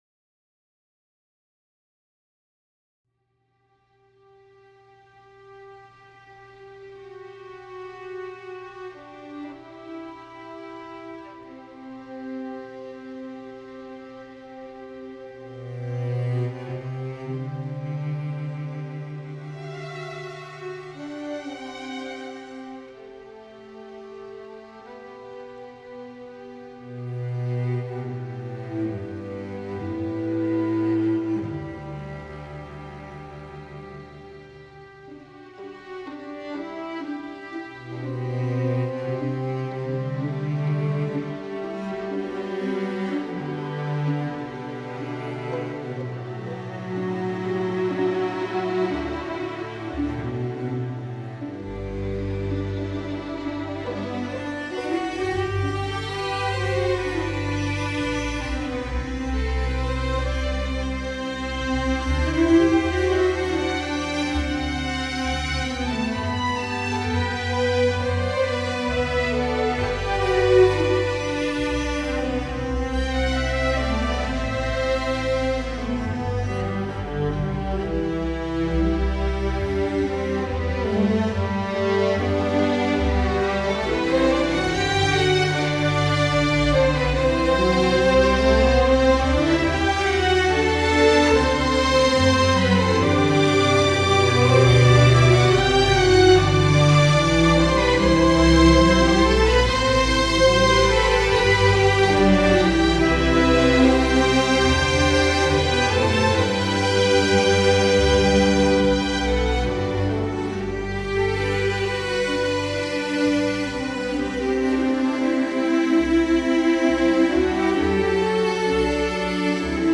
Voicing: String Orchestra Level